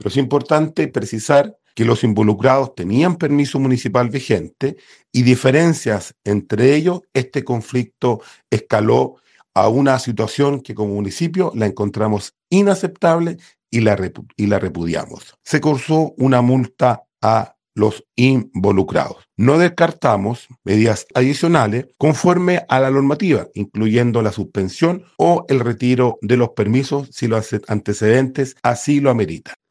cuna-alcalde-penco.mp3